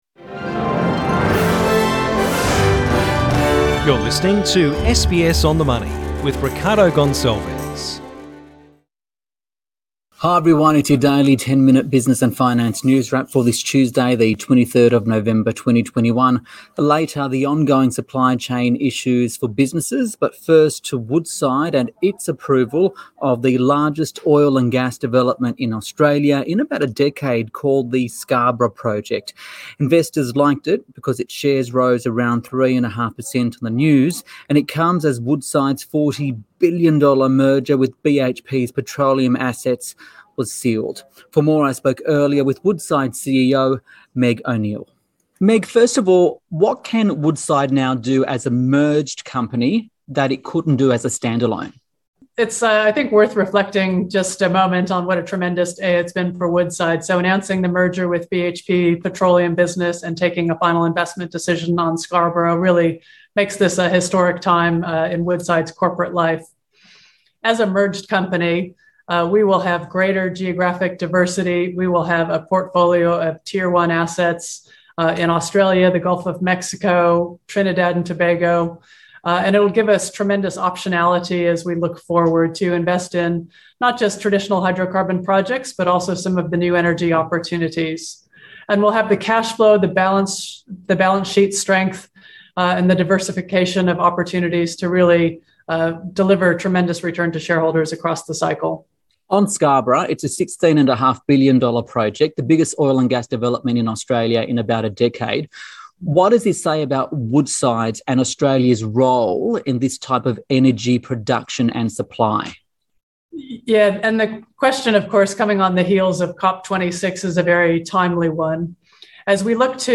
SBS On the Money: CEO Interview: Meg O'Neill, Woodside